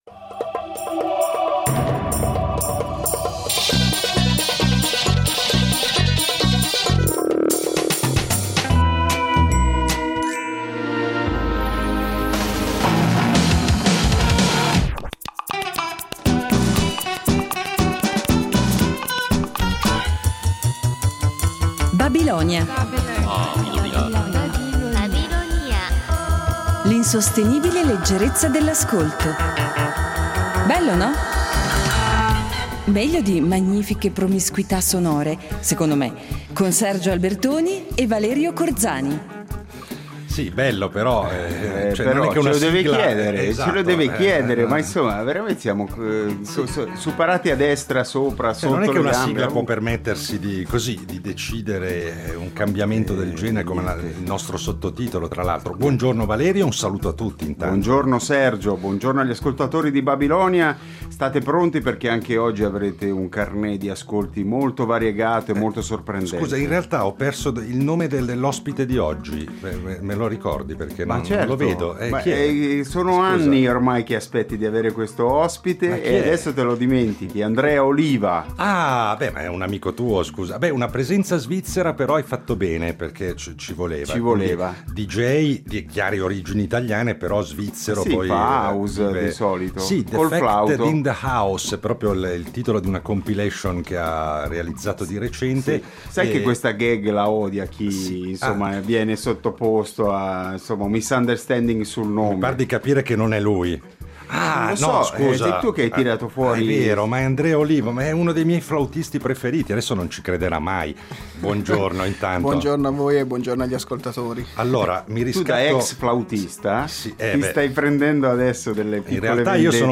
Un ospite "classico", quindi, che non ha paura di affrontare i nostri perigliosi itinerari sonori e avrà modo di dimostrare la sua babilonicità confrontandosi con un flauto andino che, ad inizio trasmissione, si troverà davanti insieme al microfono.